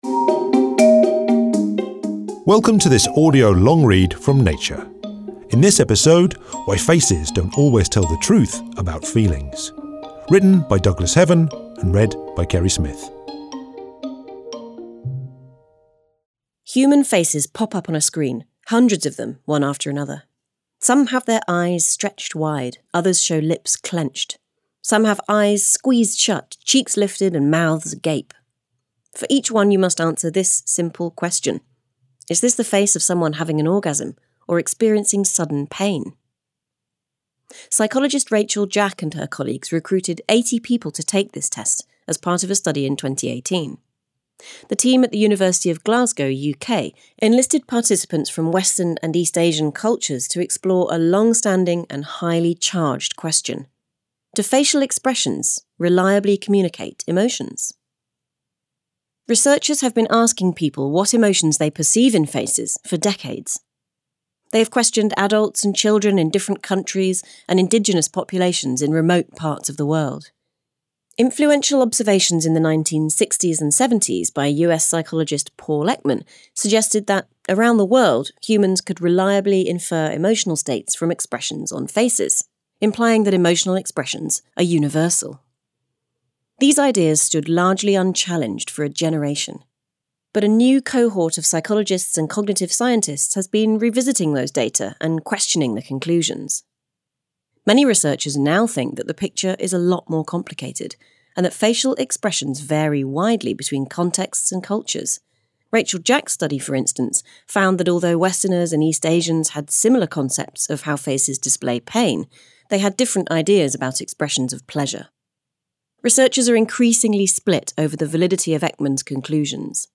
Listen to an audio version of a recent Nature feature article.